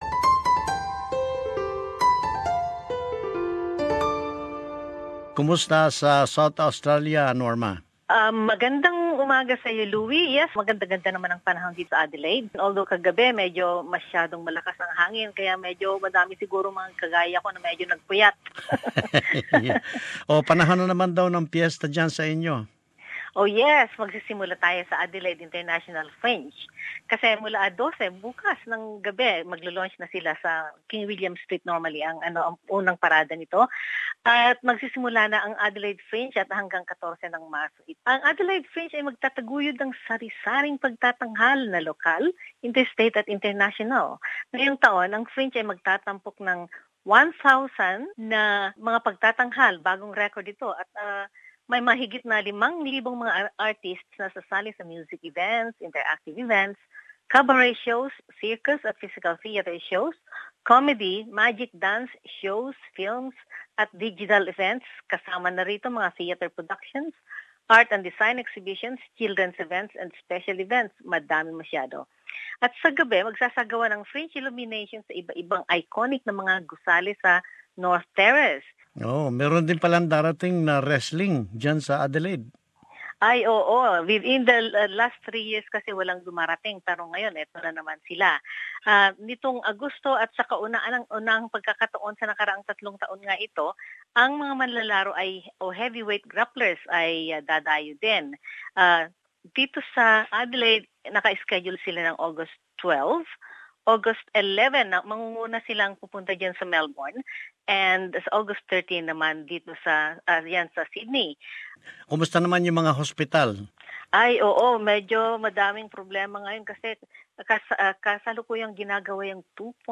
South Australia News.